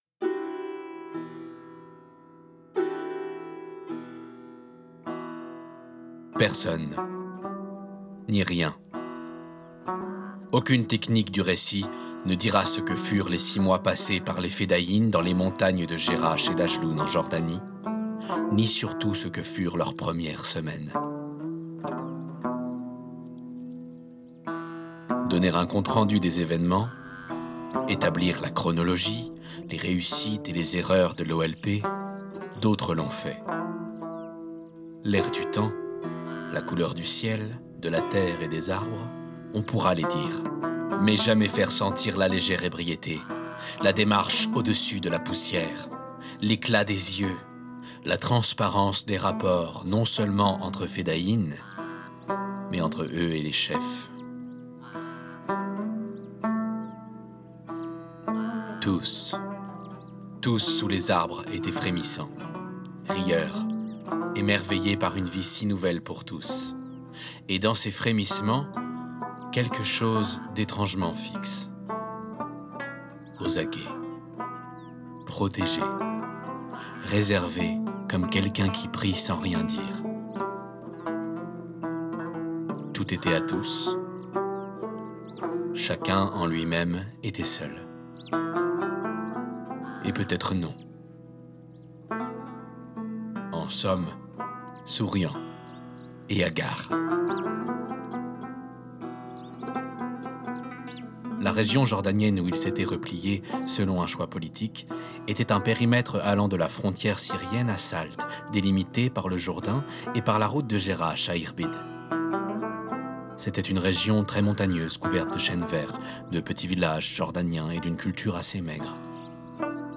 Voix off
Documentaire - "Quatre heures à Chatila" de Jean Genet